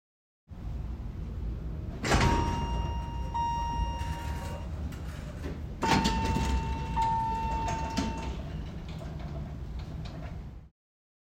The elevators in the music building on my campus use separate call-buttons
and create a canon at the minor second, if called one right after the other,
as they arrive on the 3rd floor
CPMC building, UCSD, San Diego
elevatorcanon.wav